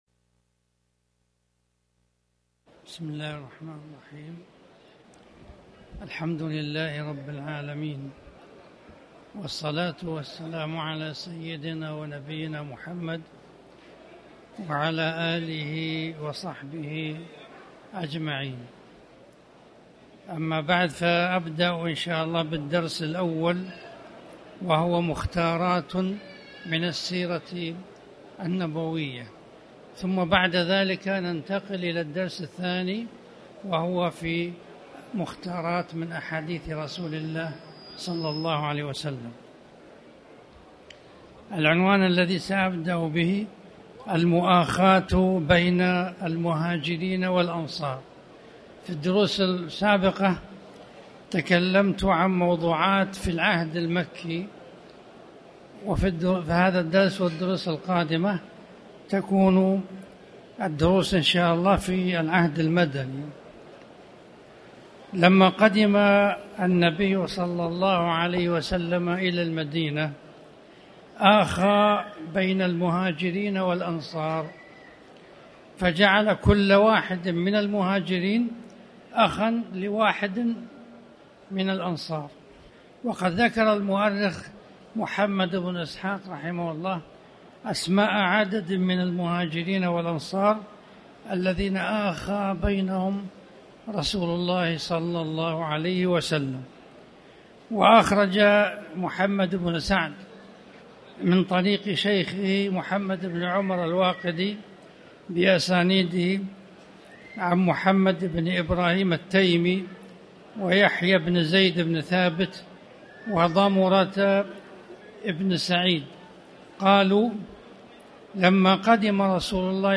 الدرس الأول: مختاراتٌ من السيرة النبوية